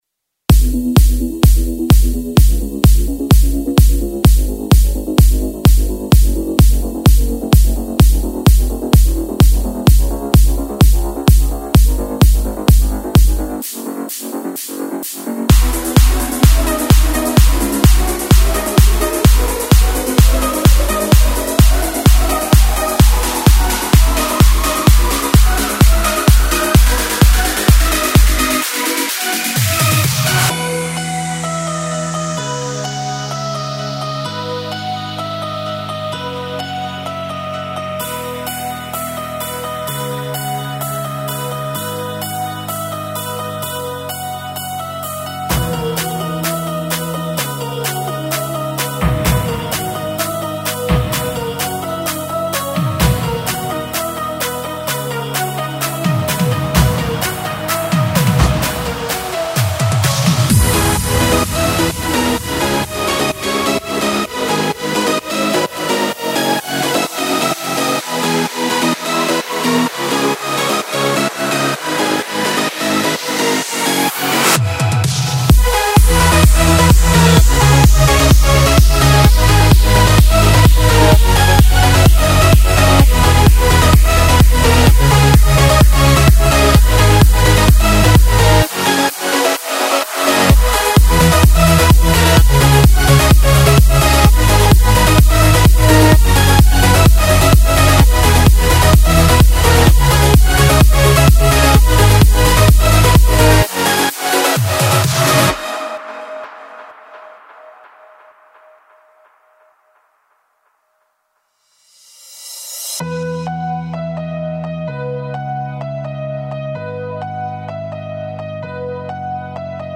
party - soiree - dj - nuit - beat